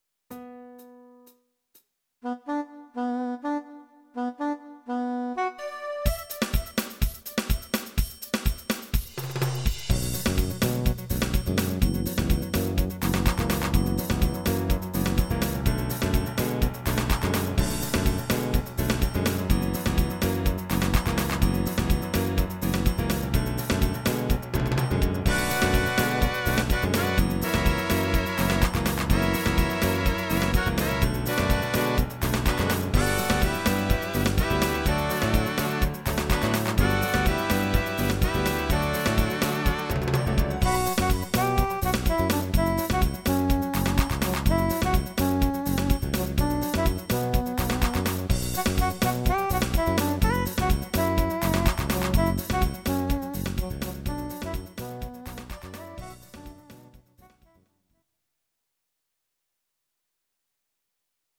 These are MP3 versions of our MIDI file catalogue.
Please note: no vocals and no karaoke included.